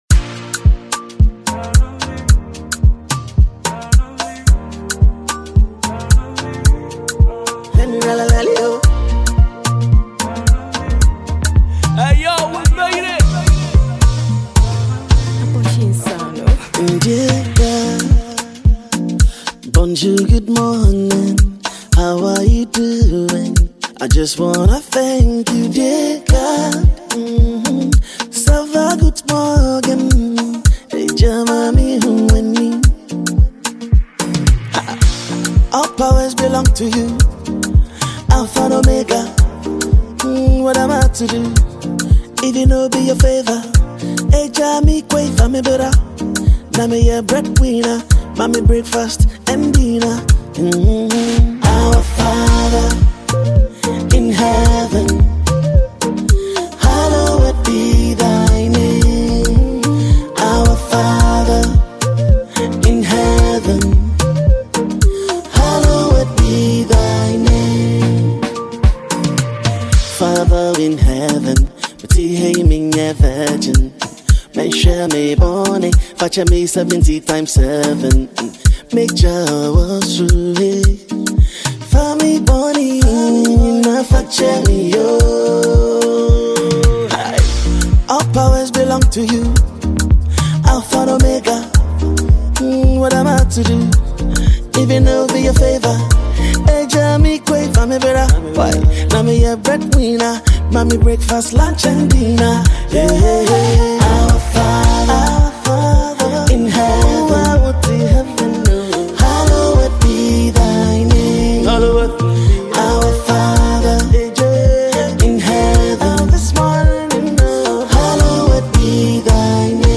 a free Mp3 Afrobeat song dedicated to God Almighty.